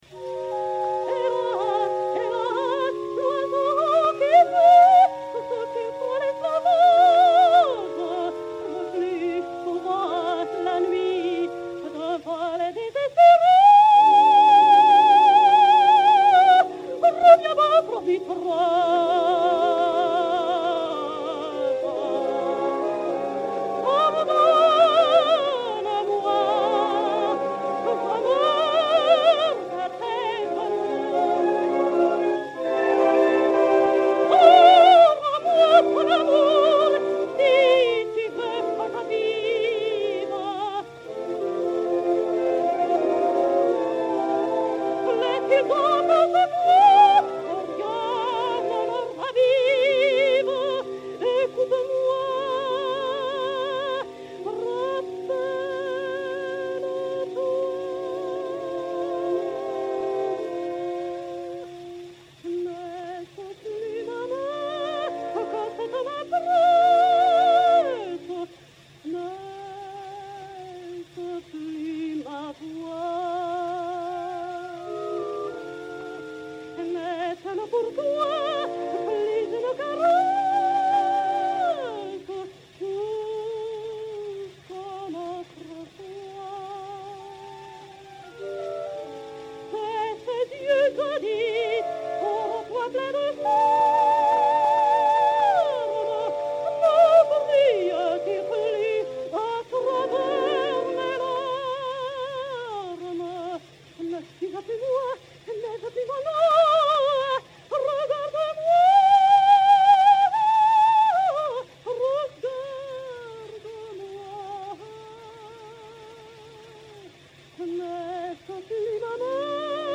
soprano suédois
Sigrid Arnoldson (Manon) et Orchestre
Disque Pour Gramophone 33844, mat. 108481, enr. à Berlin le 06 mai 1910